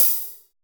FUNKY 1 2 F.wav